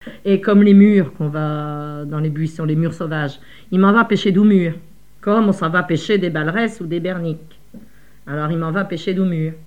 expressions en patois
Catégorie Locution